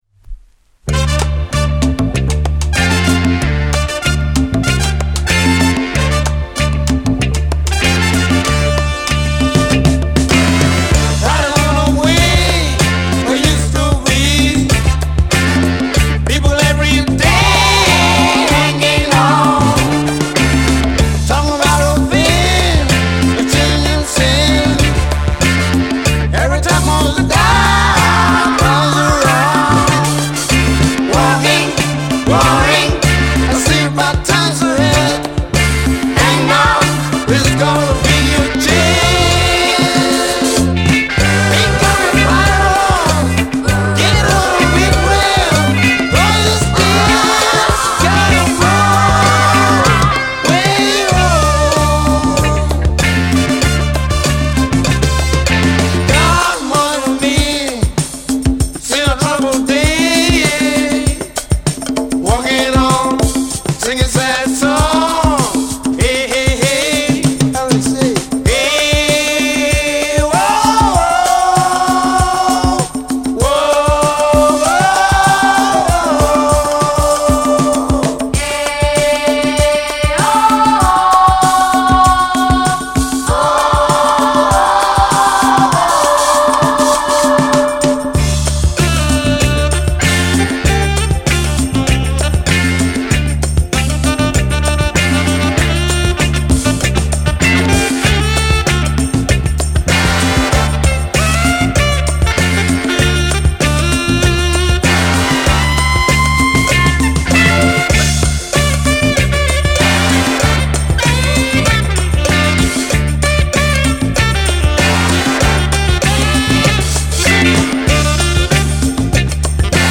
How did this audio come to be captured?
recorded in Italy in 1974